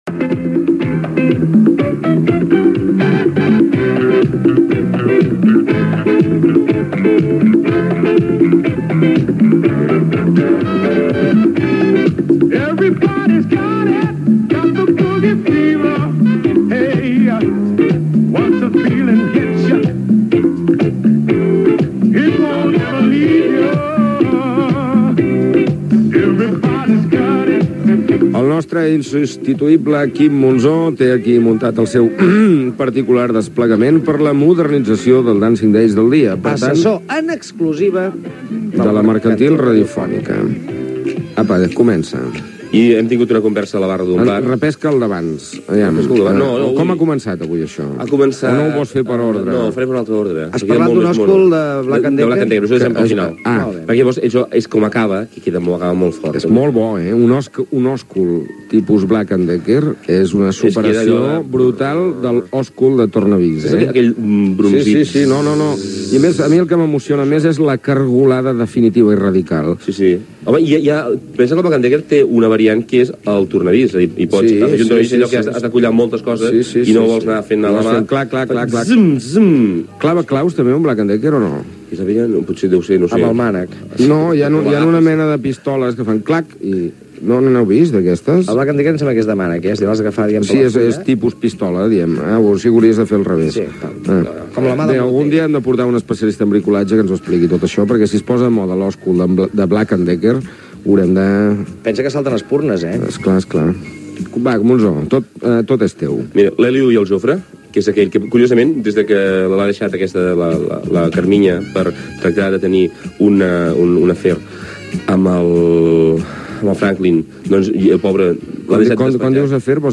Comentari del capítol del serial brasiler "Dancing days" que emetia TV 3,a la tarda, i diàleg entre els integrants de l'equip del programa Gènere radiofònic Entreteniment